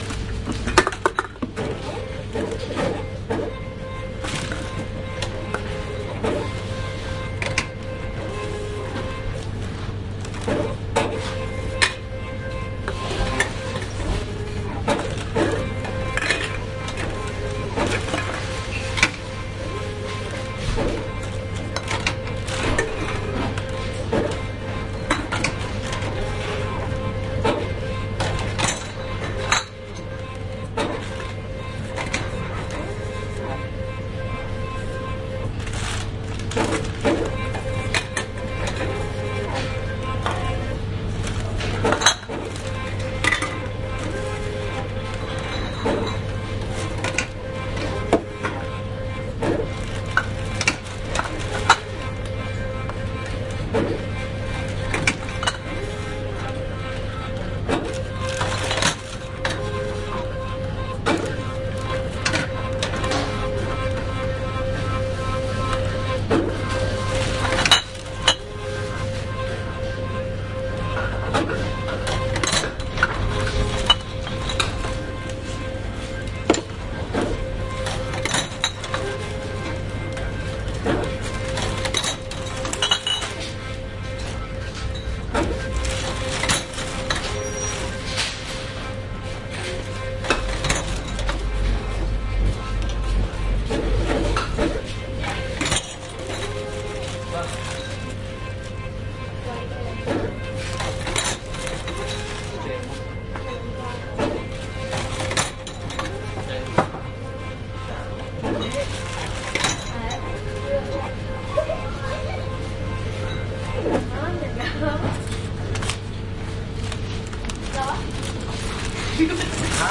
现场记录 " 储瓶机
描述：存瓶机...。HIMD双耳录音。
Tag: 双耳 现场录音 存储